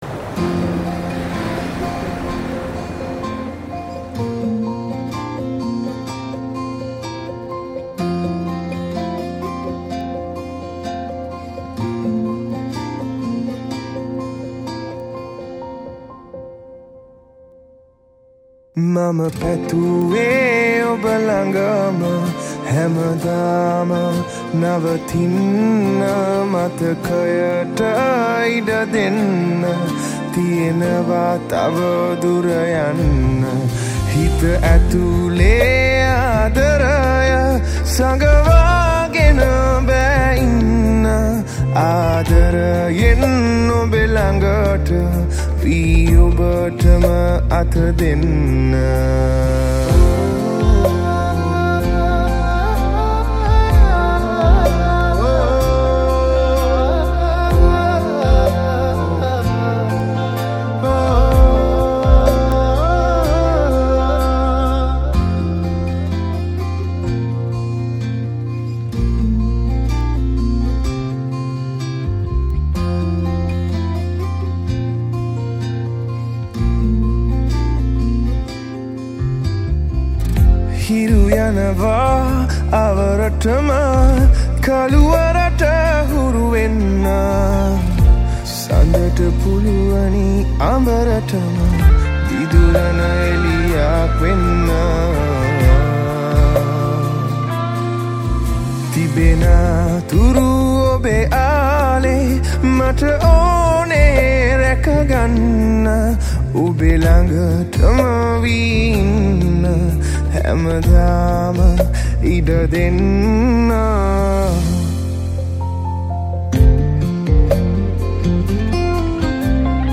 Guitars
Piano and all Synths